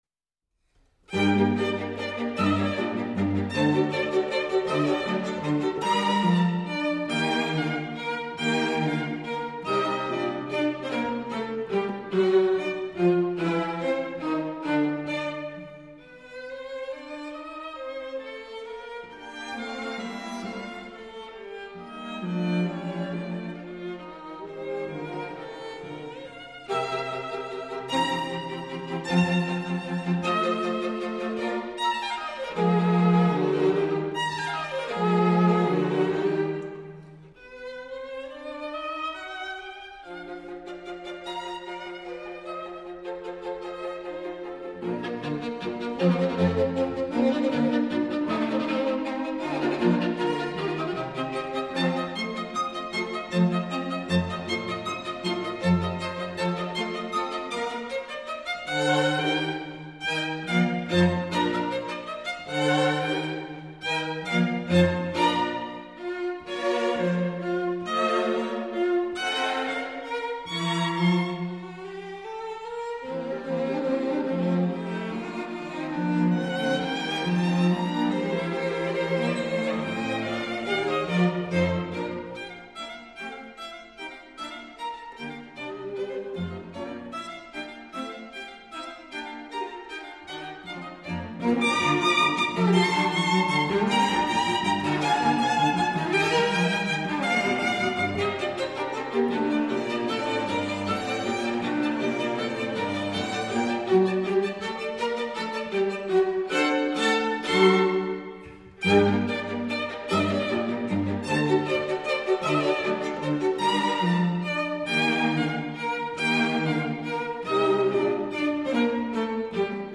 String Quartet in B flat major
Allegro